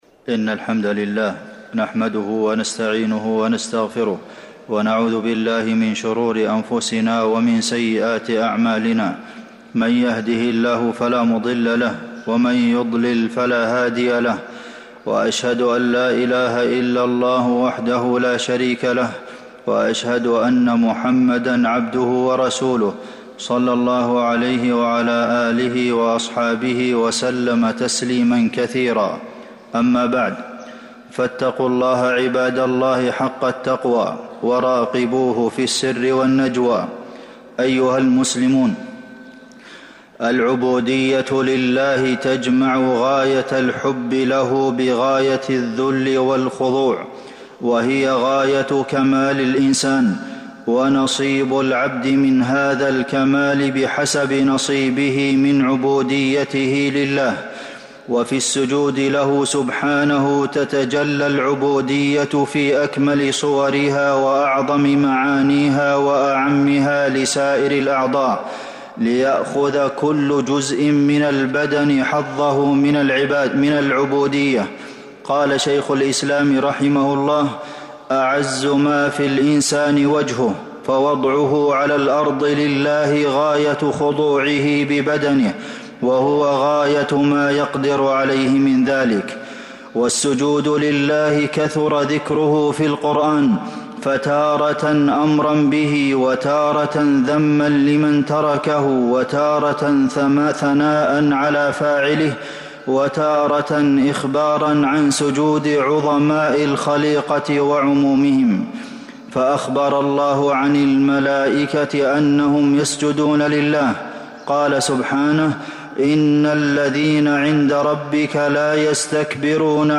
المدينة: عبودية السجود لله عز وجل - عبد المحسن بن محمد القاسم (صوت - جودة عالية